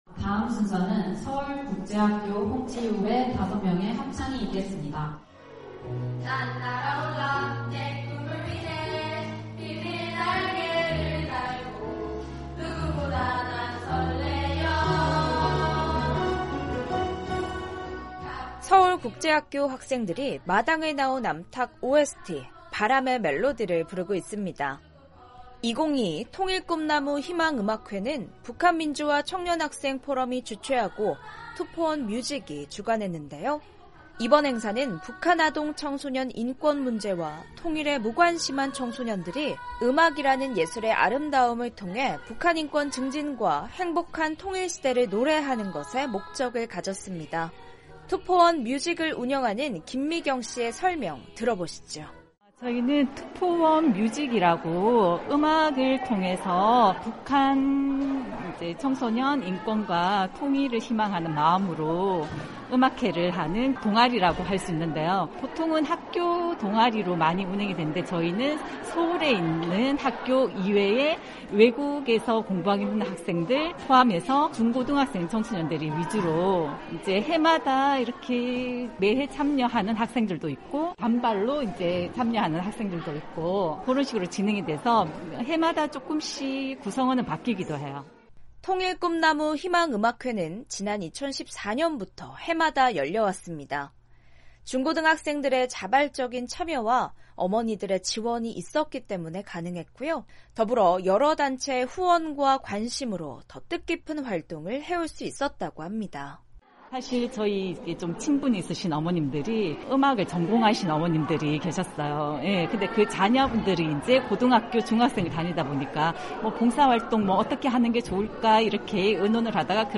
탈북민들의 다양한 삶의 이야기를 전해드리는 '탈북민의 세상 보기', 오늘은 북한민주화청년학생포럼이 주최한 '2022 투포원뮤직 통일꿈나무 희망음악회' 현장으로 안내해드립니다.